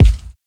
Medicated Kick 17.wav